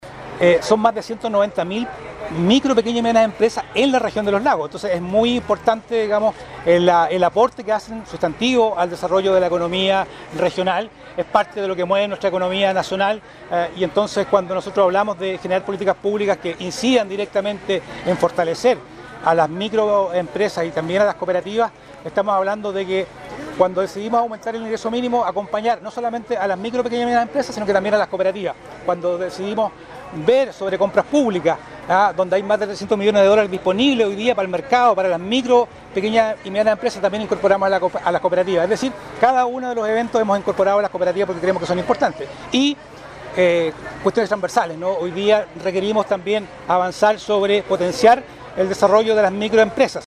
La Semana de las Mipymes y Cooperativas es una iniciativa del Ministerio de Economía, Fomento y Turismo que se realiza desde el 2012, y que este año se enfocará en el crecimiento de las empresas, a través de mejoras en su productividad, el aumento de ventas vía la capacitación en gestión empresarial y la generación de redes, indicó el Seremi de Economía